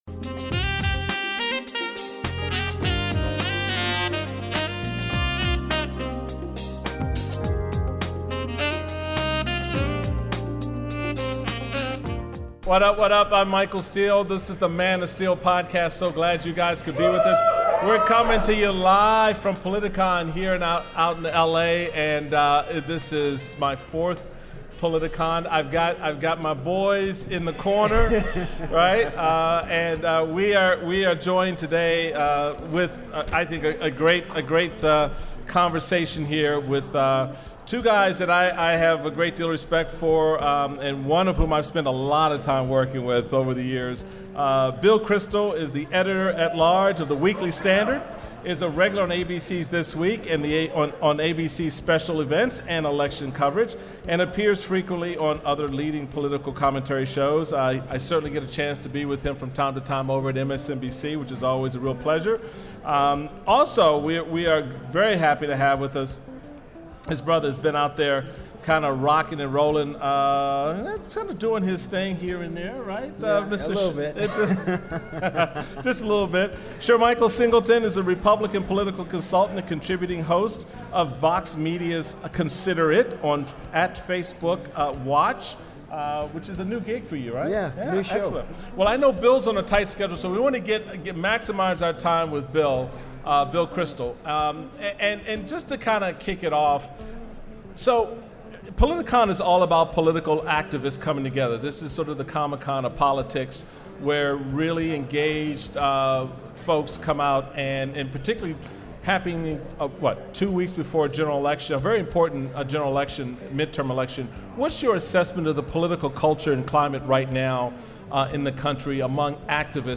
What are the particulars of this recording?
Live At Politicon!